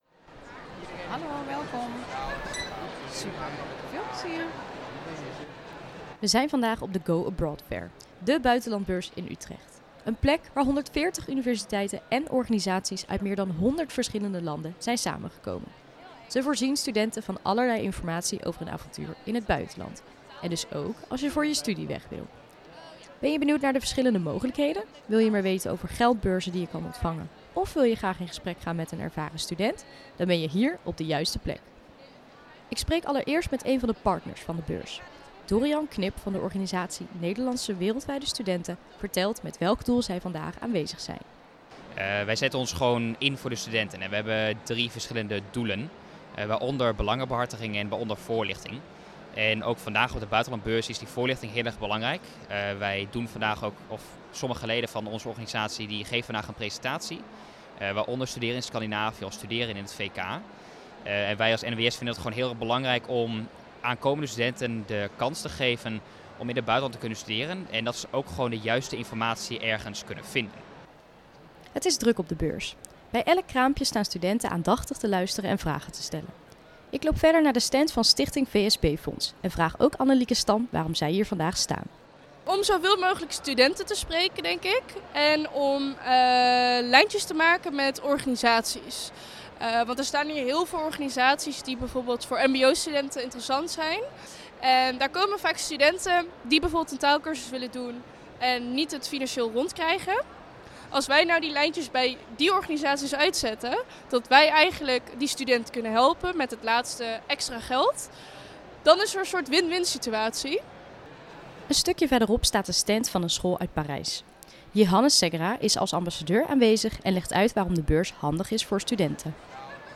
Audio-Go-Abroad-Fair.mp3